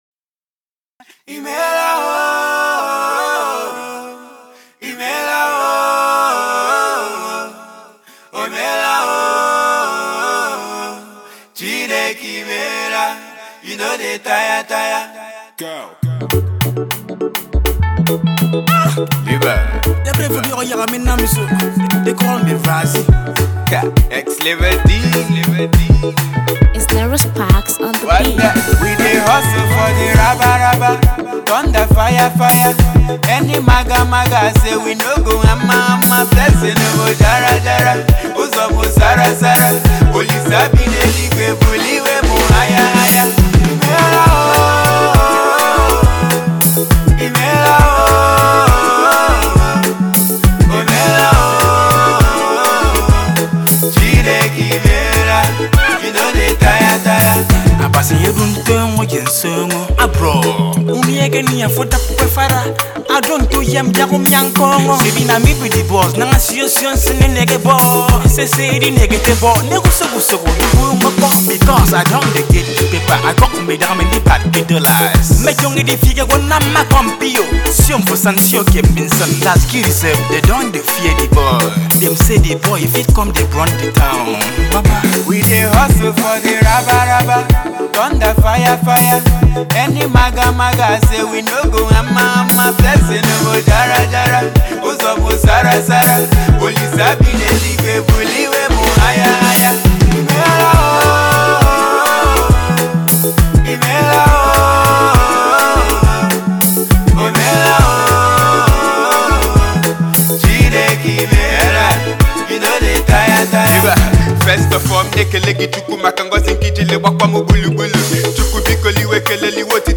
trap tongue twister
prominent Igbo indigenious Rapper
a praise rendition to the most high…